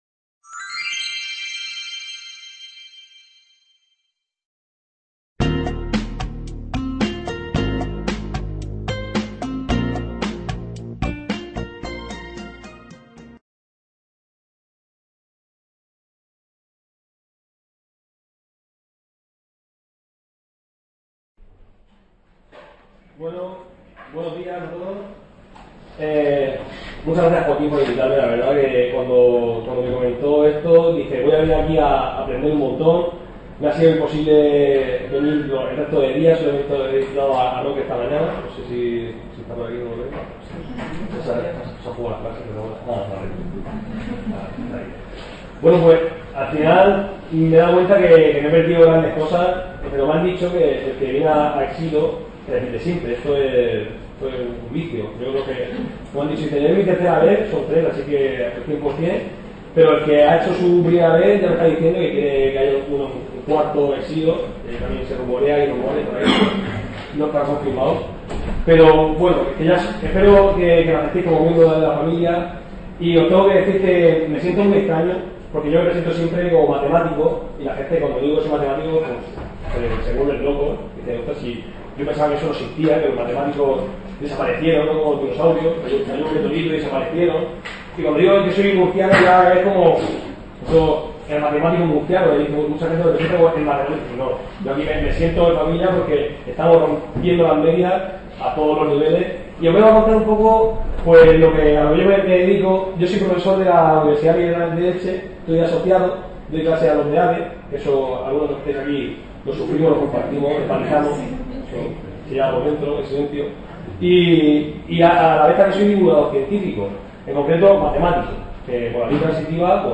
Authorship & License License Rights BY-NC-SA Público Academic Information Room Aula Virtual del Centro Asociado de Jaén Attached Resources Attached Resources Movil Audio Video